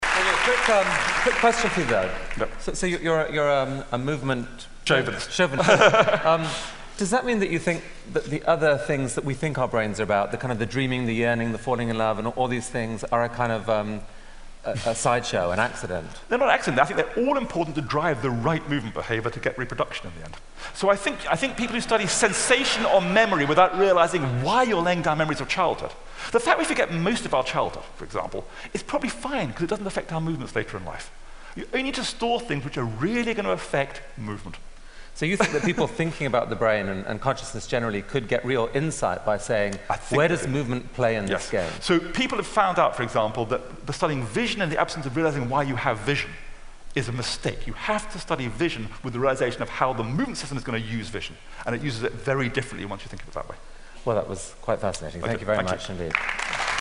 TED演讲：拥有大脑的真正原因(14) 听力文件下载—在线英语听力室